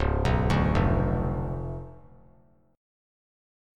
Listen to C#7b9 strummed